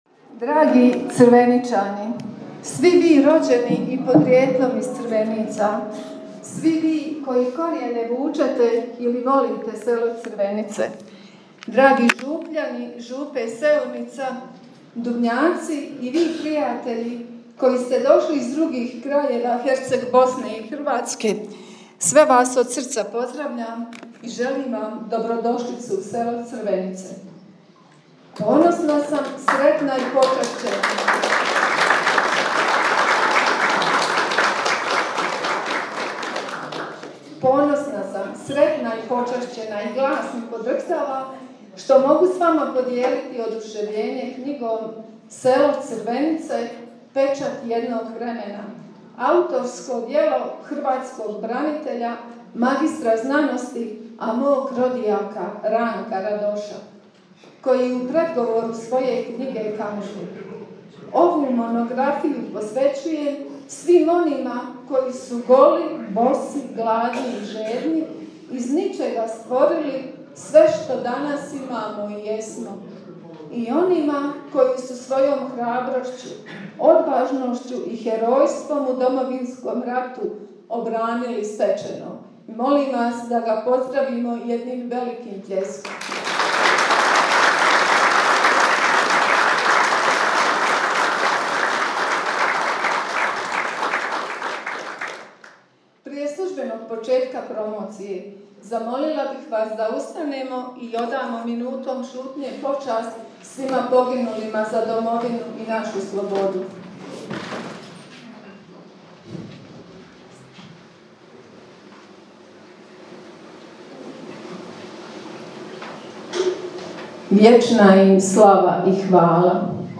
U zabavnom dijelu su nastupili crvenički TS Crvenice i članovi VIS Antonius .
TONSKI ZAPIS PREDSTAVLJANJA POSLUŠAJTE DOLJE: http